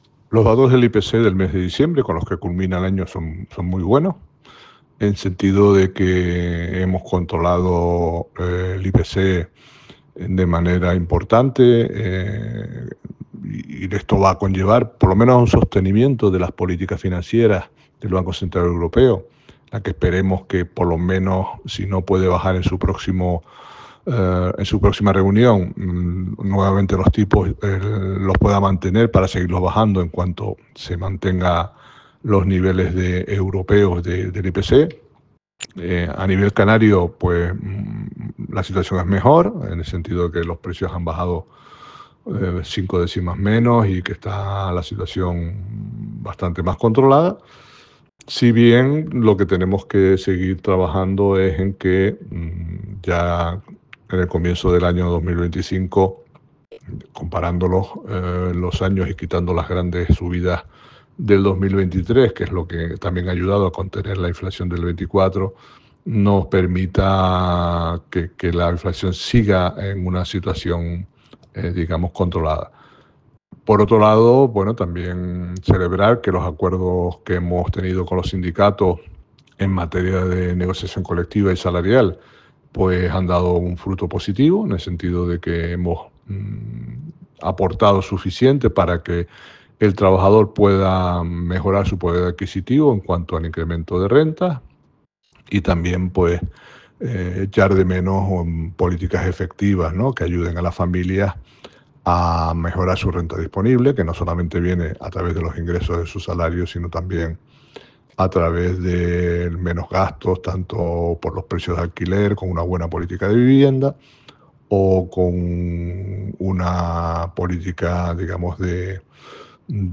DECLARACION-IPC-DICIEMBRE-2024-mp3cut.net_.mp3